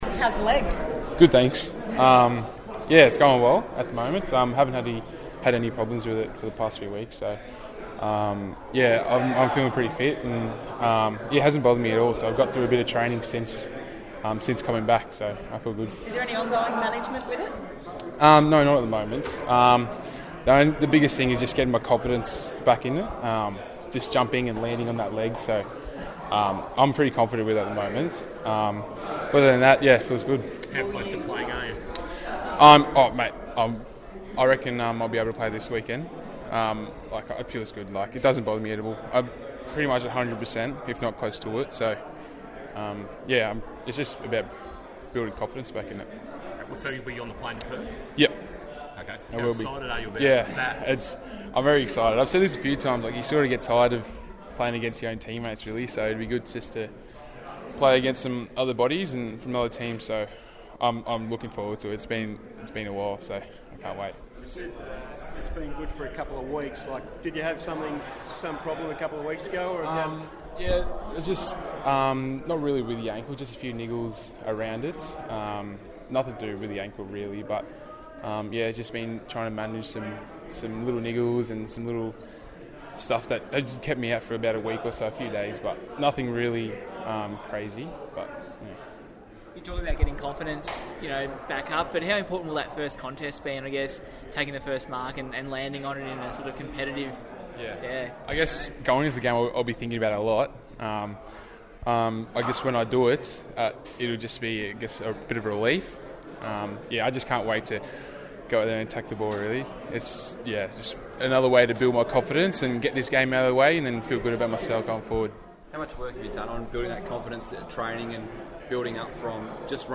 Geelong big man Esava Ratugolea spoke to the media ahead of Friday's JLT Series clash with West Coast.